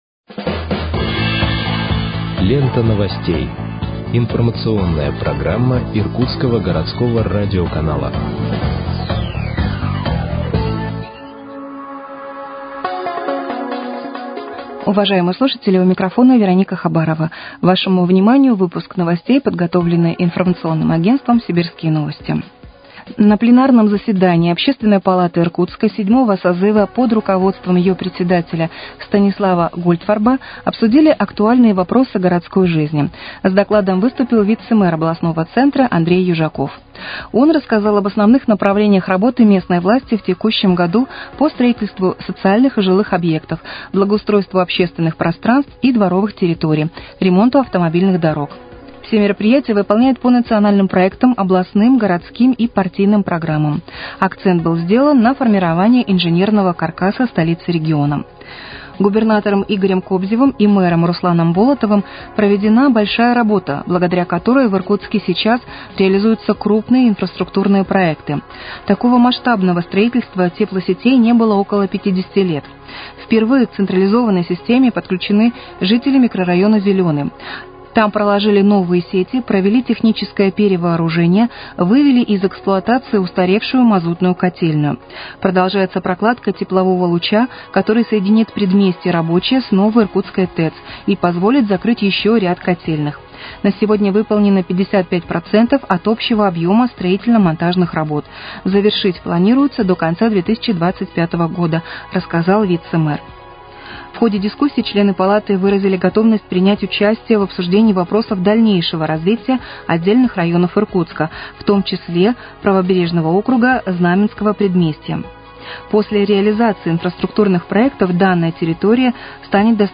Выпуск новостей в подкастах газеты «Иркутск» от 31.10.2024 № 2